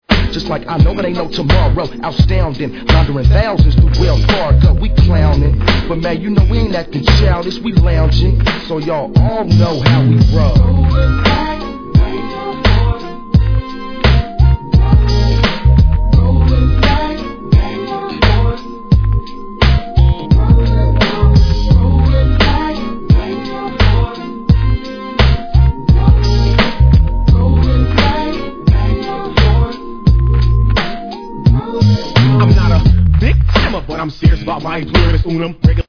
ソウルネタ好きなら昇天必至のウェッサイクラシックLP。"
Tag       BAY AREA WEST COAST